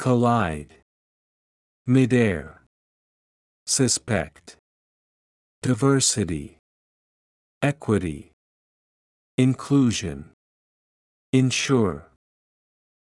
音声を再生し、強勢のある母音（＝大きな赤文字）を意識しながら次の手順で練習しましょう。
collide /kəˈlaɪd/（動）衝突する、ぶつかる
midair /ˌmɪdˈɛr/（名・形）空中（の）、宙に浮いた状態（の）